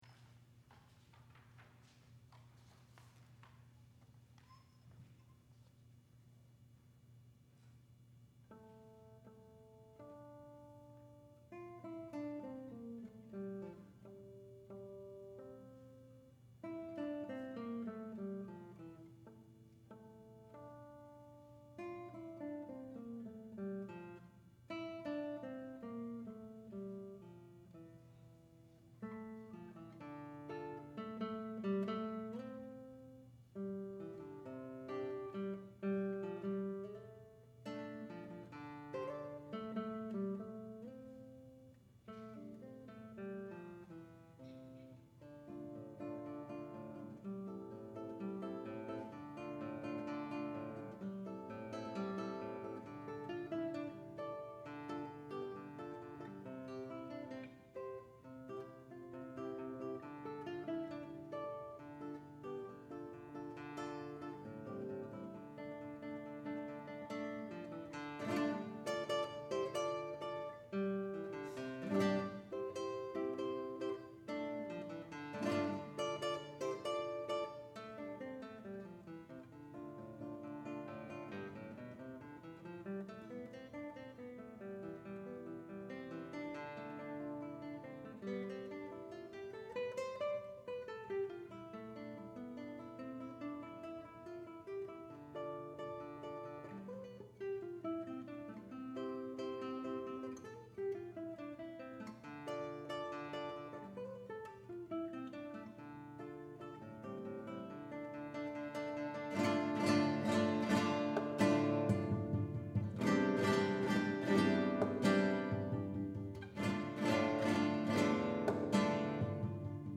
for Guitar (2006)